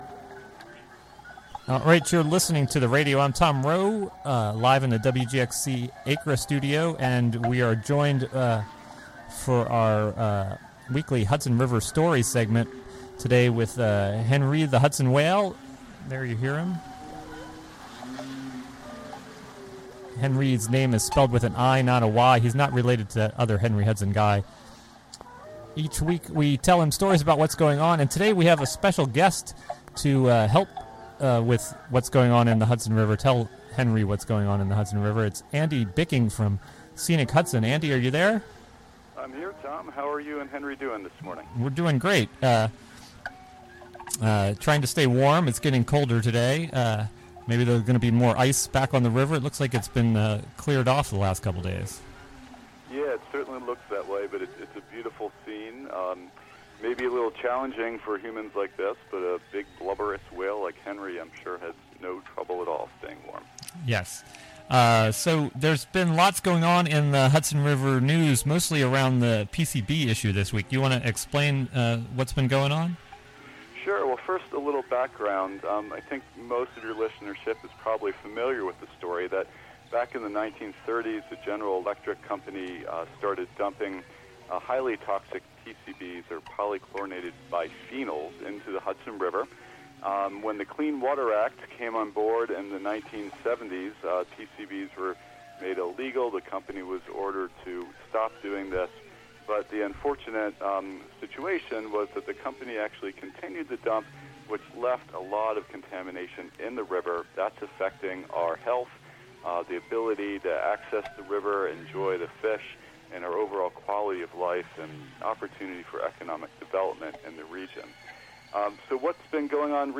11am The WGXC Morning Show is a radio magazine show fea...